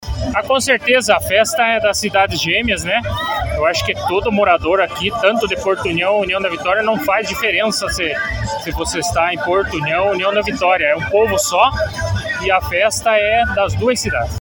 A solenidade de abertura ocorreu em frente ao Clube Apolo, reunindo autoridades, organizadores e a comunidade local.